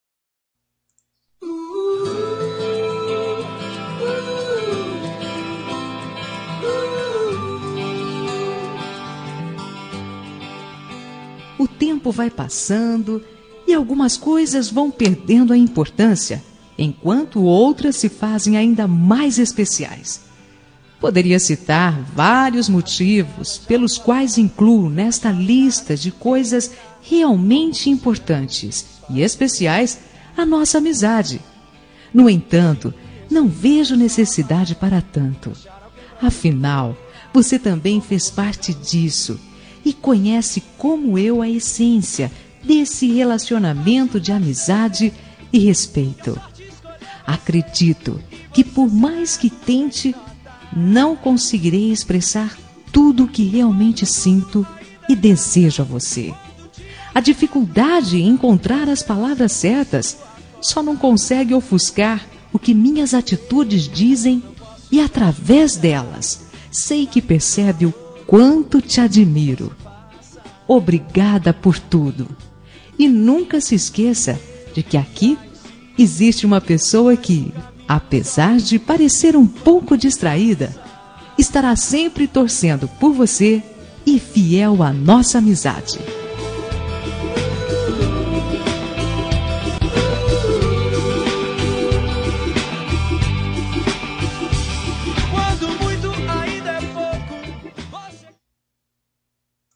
Telemensagem de Amizade – Voz Feminina – Cód: 95
95-amizade-fem.m4a